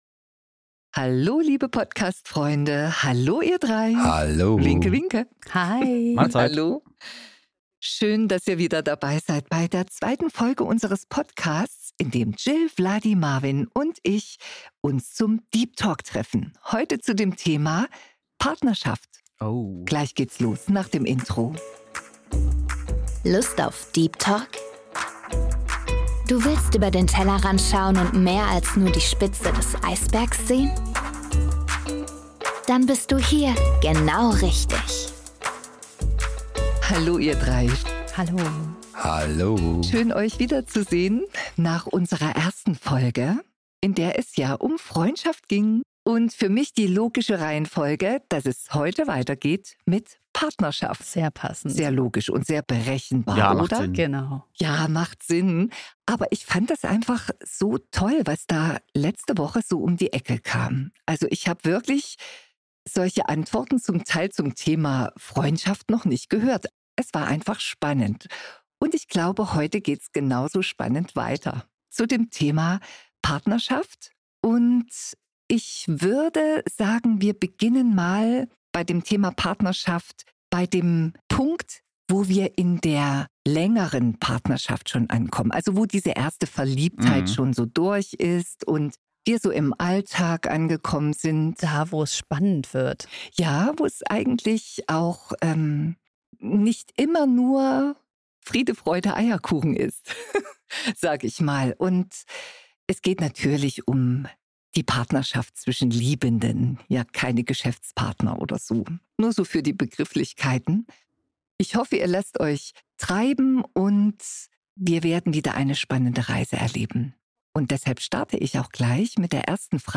Deine Podcast-Freunde reden in dieser Folge über Nähe und Distanz, über Erwartungen, Enttäuschungen und die Kunst, sich gegenseitig Freiheit zu lassen. Ein ehrliches Gespräch über Liebe, Streit, Sehnsucht – und die Frage: Wie bleibt man ein Wir, ohne sich selbst zu verlieren?